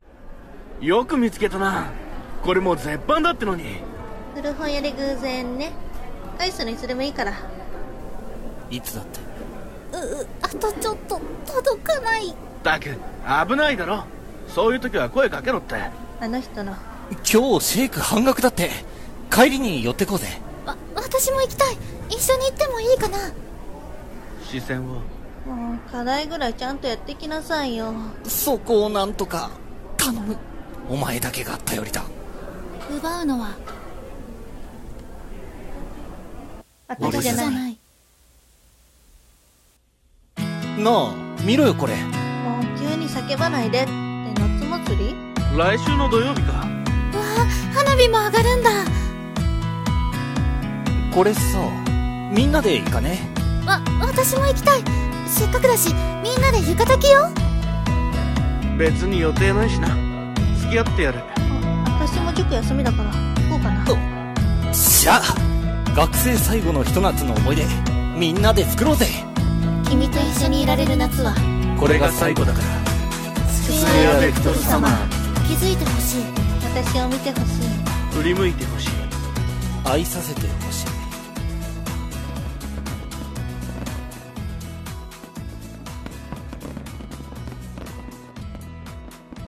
【4人声劇】スクエア・ベクトル サマー